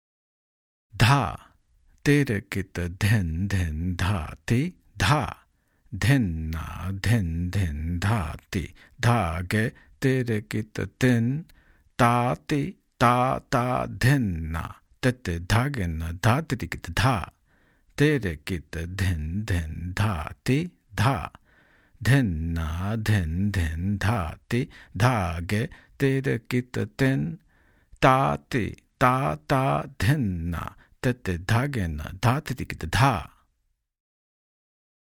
In this section and the next, there are practice variations, accompaniment variations, and theka improvisations in vilambit and madhyalay tempos (slow and medium):
• Accompaniment variations present embellishments and fills which are more typical of accompaniment and are not as repetitive.